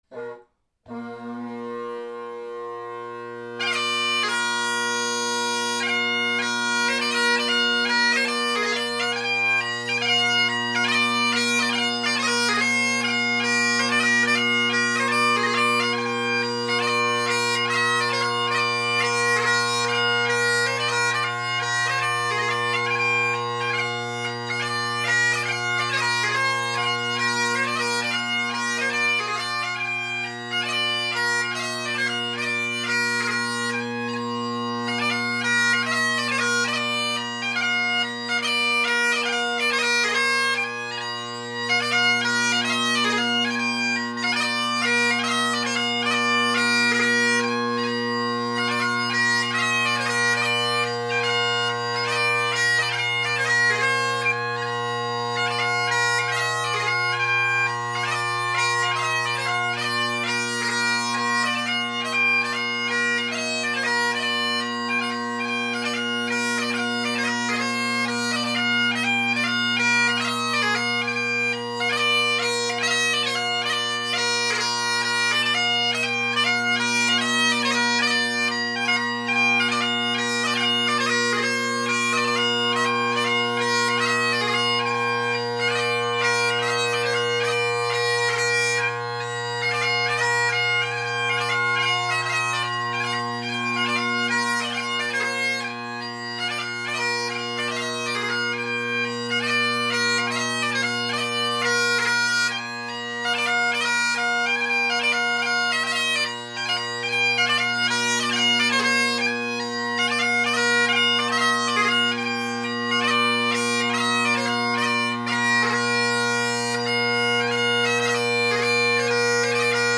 Robertson bagpipes with Robertson Rocket drone reeds
Drone Sounds of the GHB
The bass is big and the tenors mellow and with where I put the recorder (same spot as usual) you get a lot of bass without much tenor. There are spots in the recordings where you can hear the blend as for the most part I’m usually just meandering around the room, so they’re there, you’ll just have to wait for them.